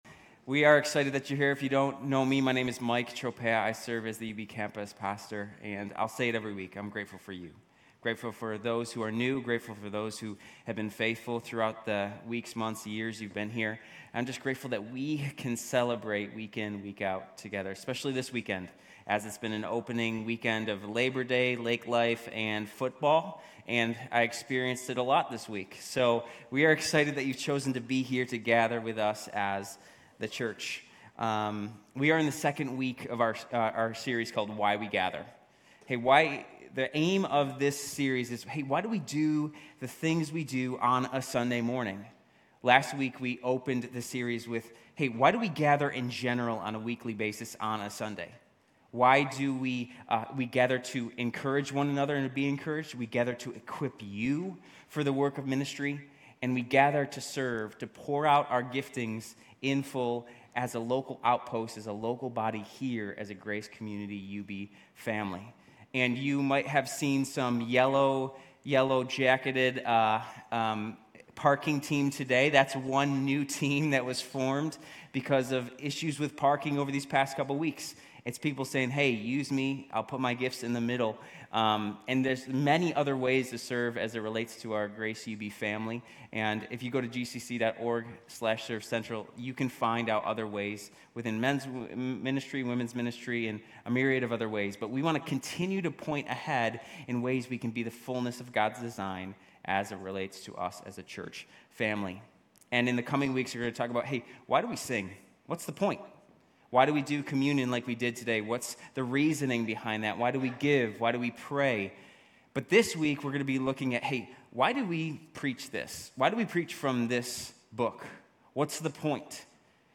GCC-UB-September-3-Sermon.mp3